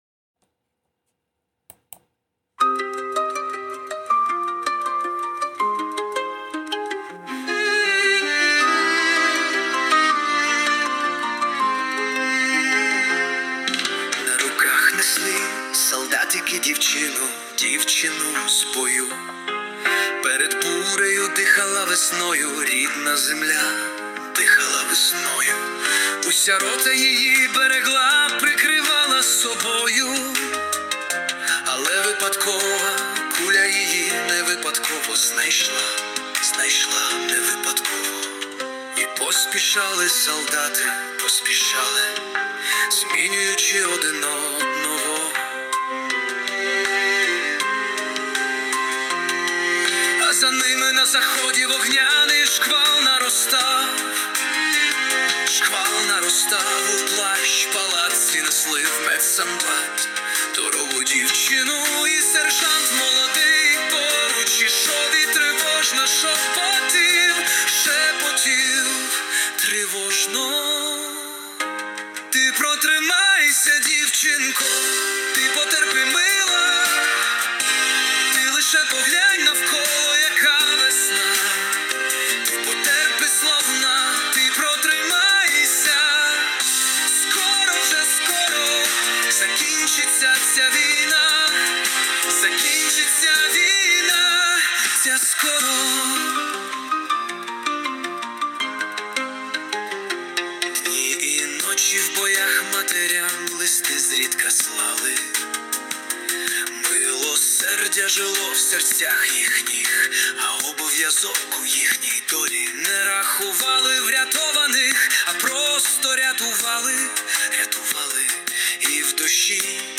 Болісна, зворушлива пісня...та чи скоро??? frown 17 17